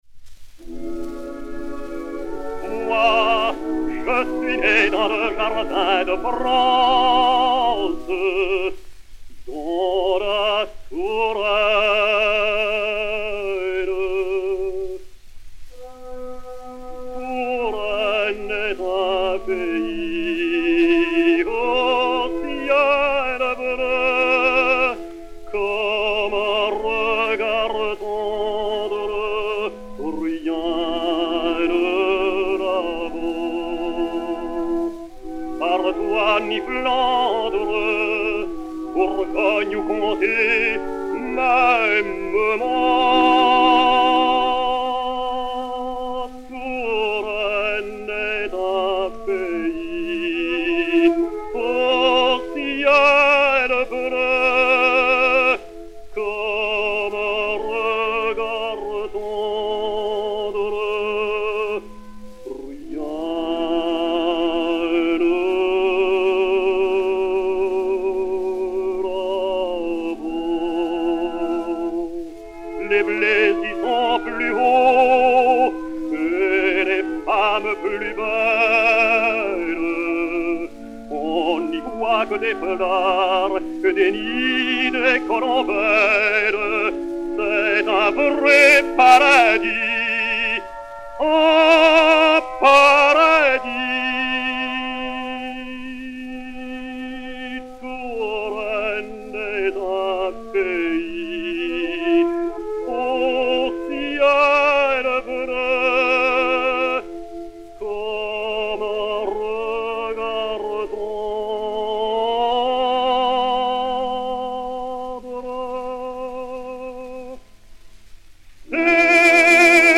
Emilio de Gogorza (Panurge) et Orchestre